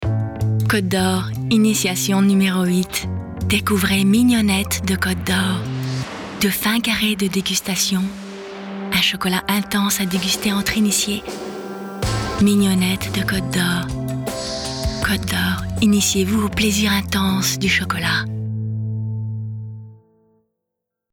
Voix off
35 - 50 ans - Mezzo-soprano